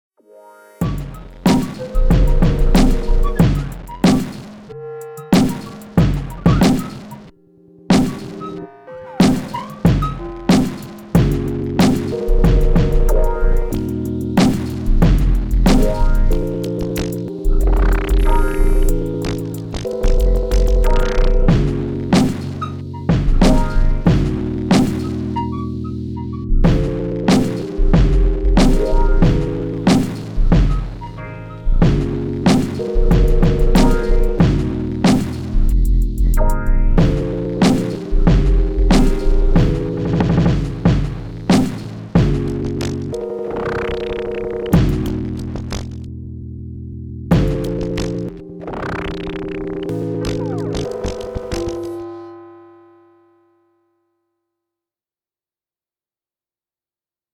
rytm